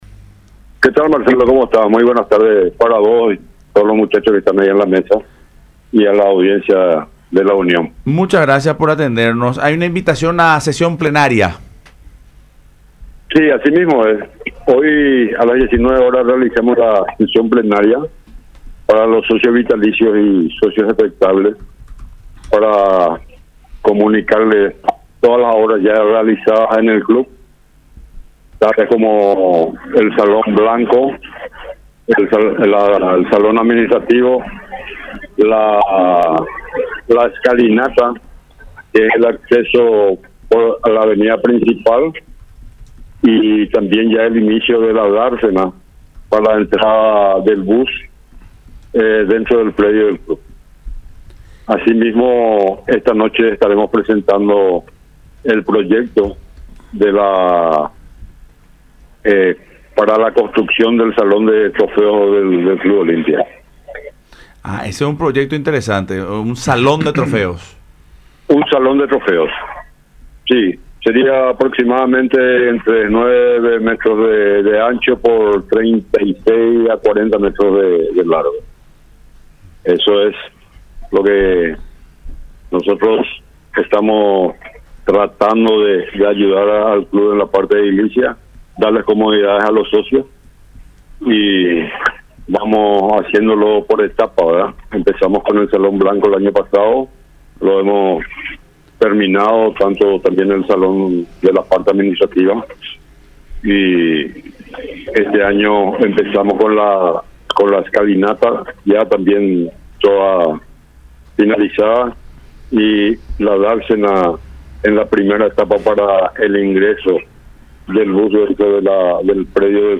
“A las 19:00 horas hacemos una sesión plenaria para comunicar todas las obras que se llevan a cabo en el club. Será en el Salón Blanco”, expresó en contacto con Fútbol Club a través de Radio La Unión y Unión TV.